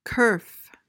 PRONUNCIATION: (kuhrf) MEANING: noun: 1.